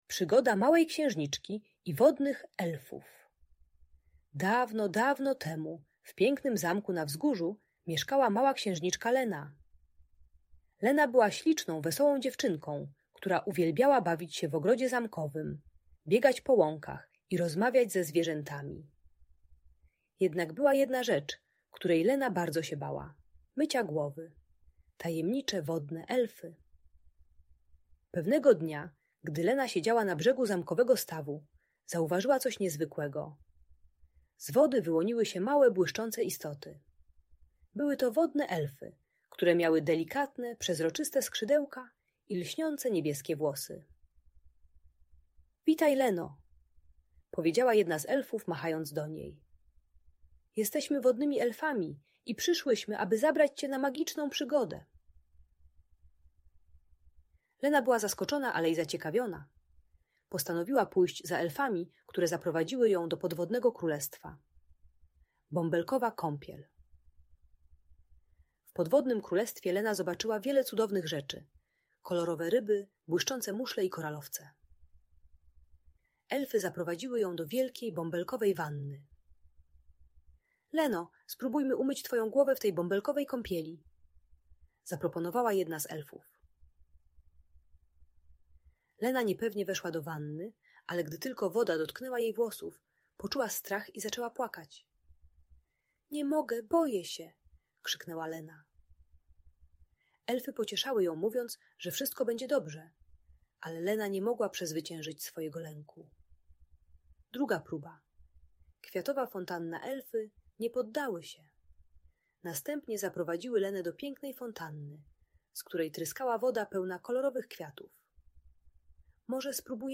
Przygoda Małej Księżniczki i Wodnych Elfów - Audiobajka